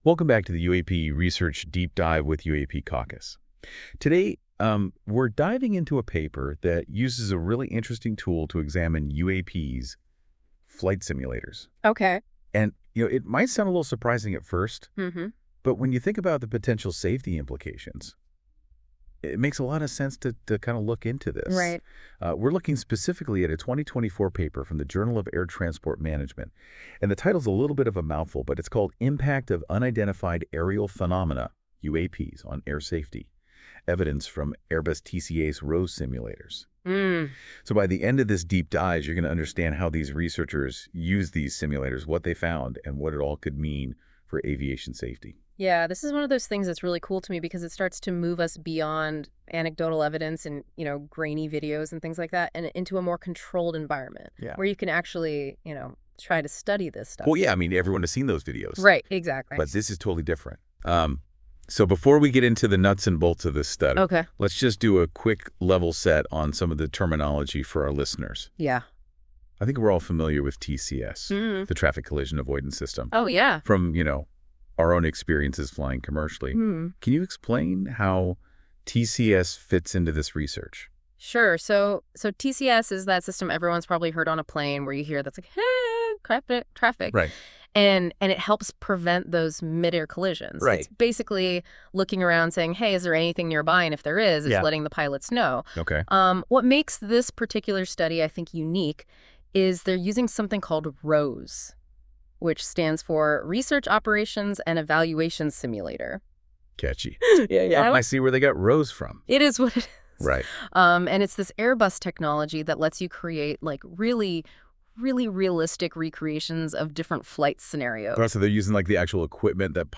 Audio Summary
This AI-generated audio may not fully capture the research's complexity.